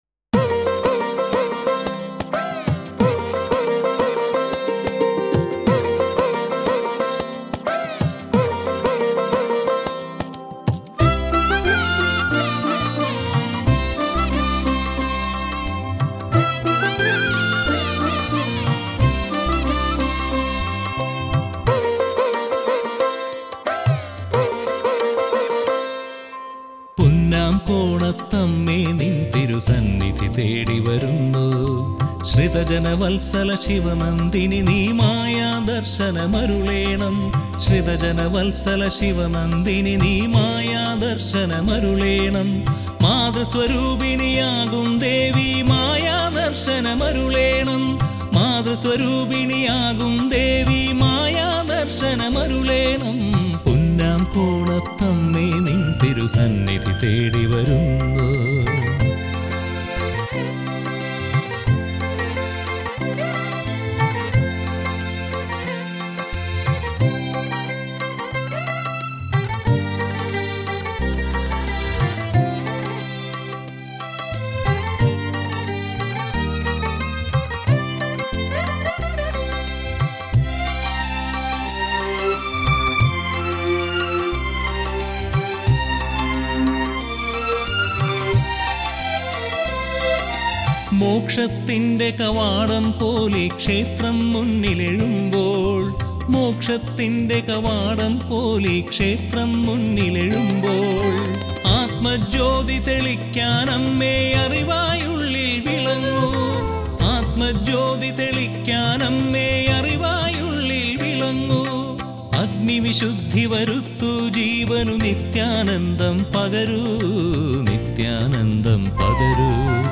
Malayalam devotional songs
sacred temple music